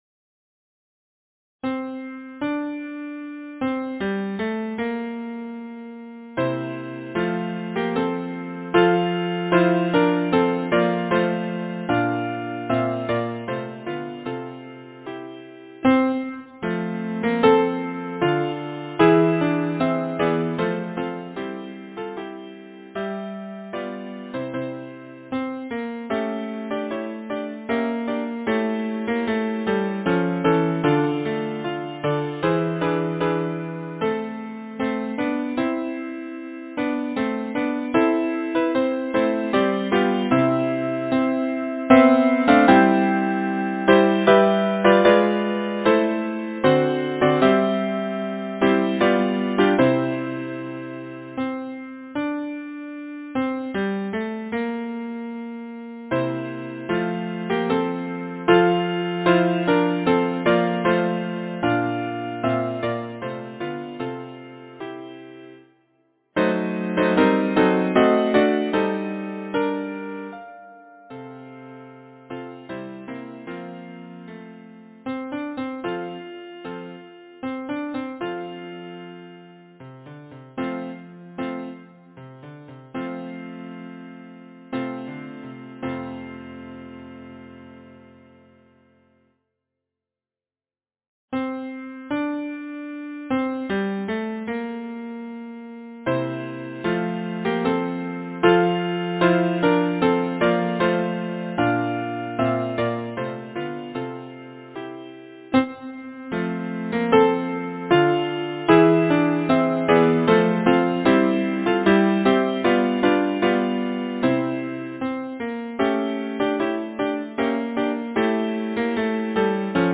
Title: Blow, gentle breeze Composer: Josiah Booth Lyricist: Edward Oxenford Number of voices: 4vv Voicing: SATB Genre: Secular, Partsong
Language: English Instruments: A cappella